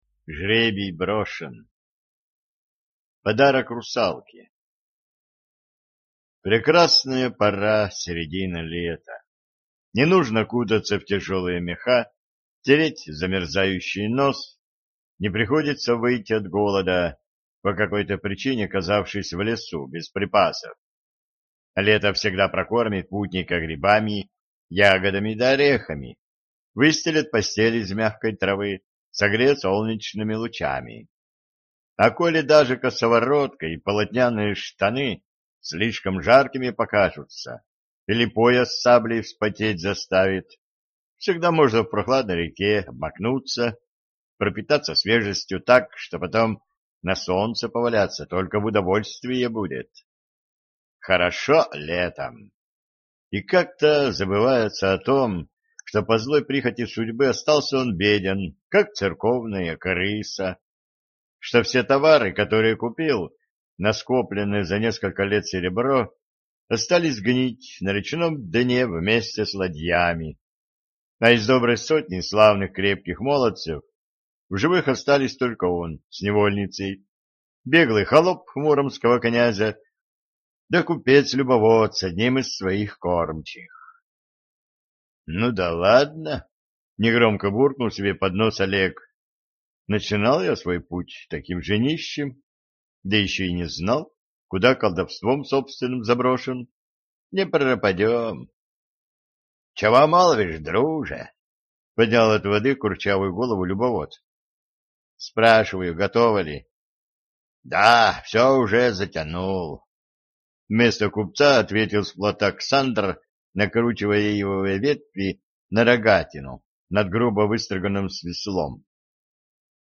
Аудиокнига Жребий брошен | Библиотека аудиокниг